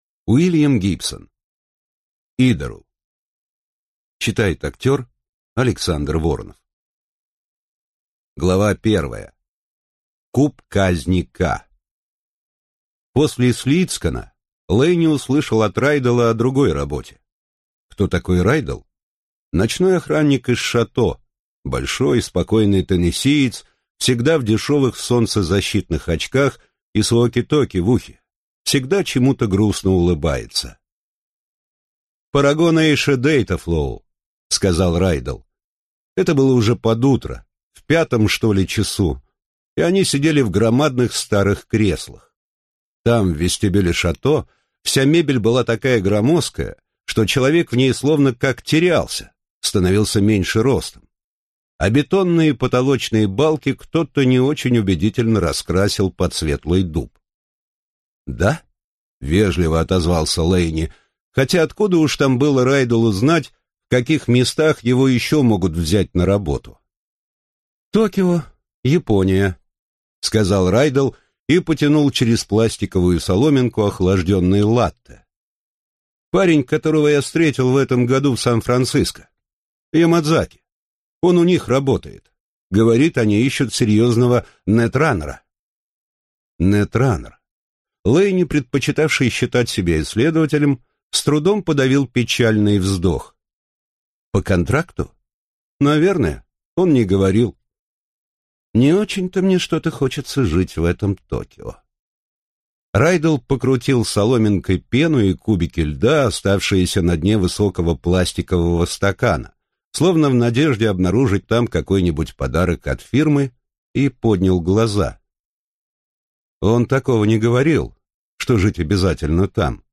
Аудиокнига Идору | Библиотека аудиокниг